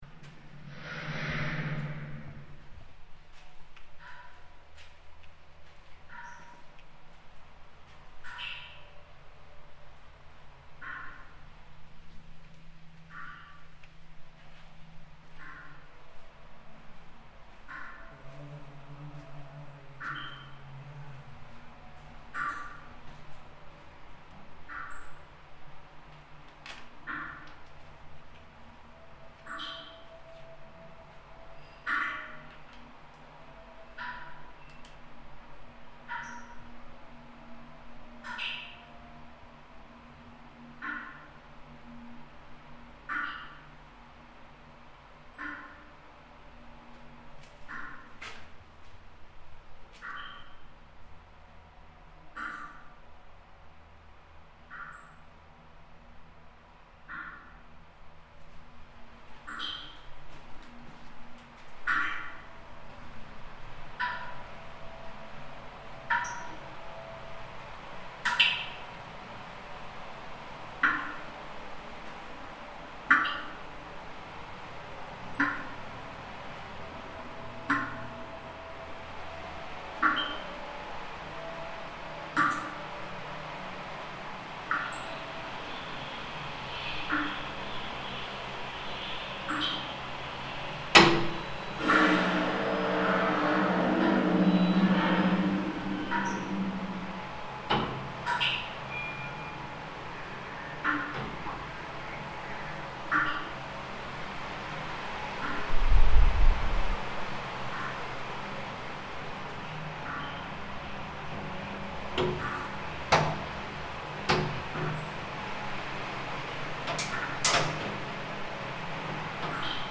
Now The museum and art space reflect the city initiative to use culture to celebrate the birth of a new era, whilst remembering its intense past. The underground long and reverberating dark corridors are accessed along a creepy 200-metre tunnel that blasts out eerie soundscapes, almost resembling a dark movie.
It intends to unearth the memory of tens of thousands of people who were imprisoned and executed by the communist regime as an acoustic experience of intrinsic value. An excellent re-imagining of a Communist-era nuclear bunker scenario through sound installations that can inspire the work of a sound designer like myself.